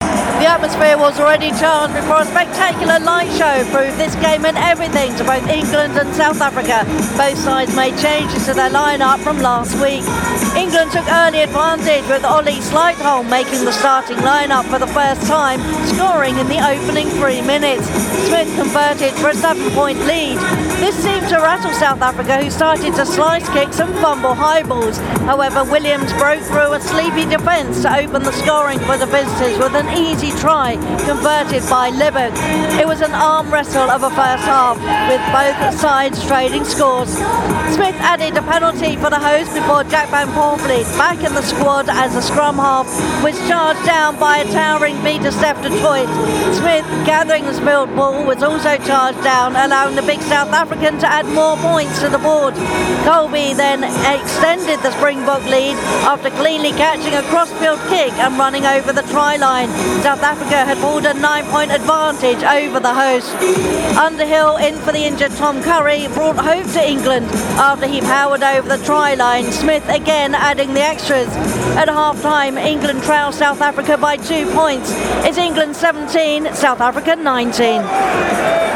Half-Time at the Allianz Stadium, Twickenham: England 17 - 19 South Africa
Half-Time at the Allianz Stadium, Twickenham: England 17 – 19 South Africa Home / Local News / Half-Time at the Allianz Stadium, Twickenham: England 17 – 19 South Africa